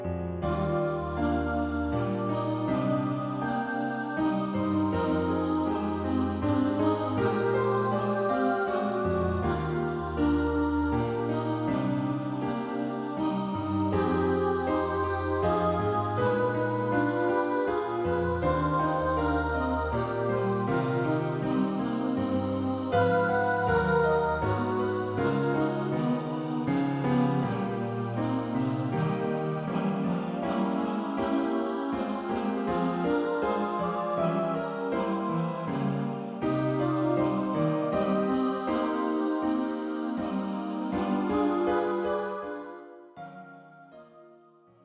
Choir (SATB) and Piano. A song for Christmas.
This is quite a difficult piece with modern harmonies.